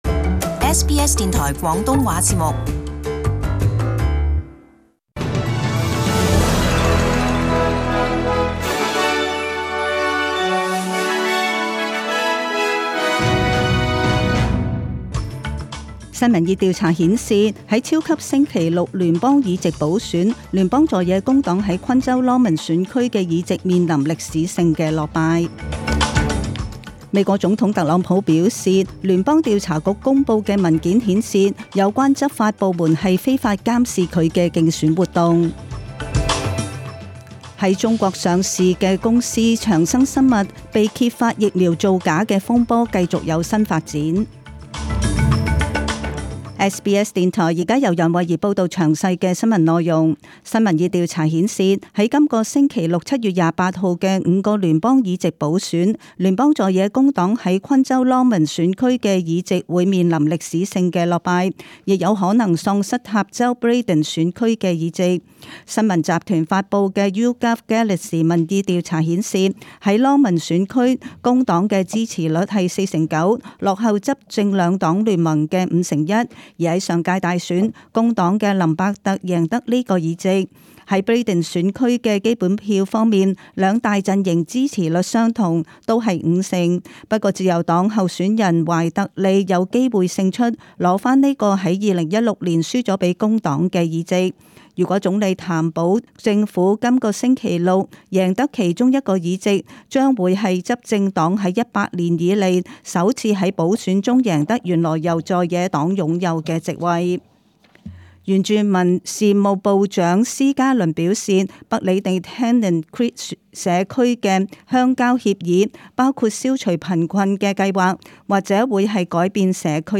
请收听本台为大家准备的详尽早晨新闻。
SBS Cantonese 10am news Source: SBS